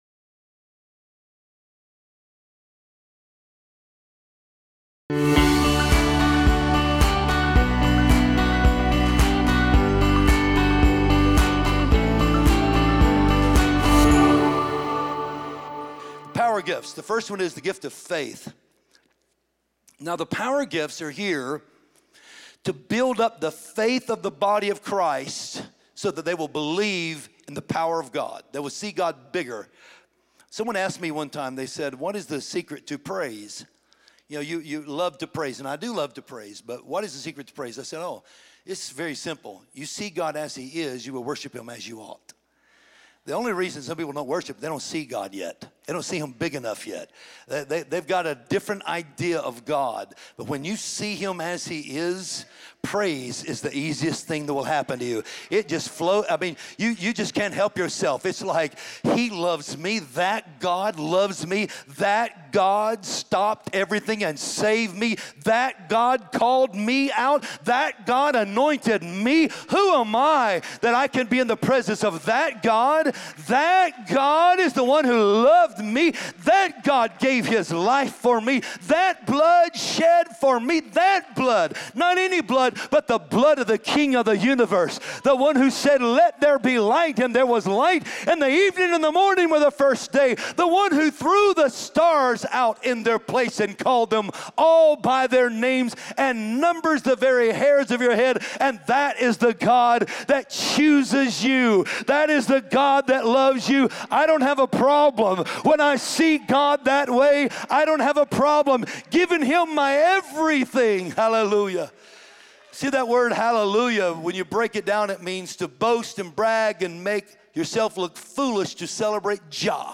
Experience God’s Power in Your Life! Watch this sermon on my YouTube Channel here: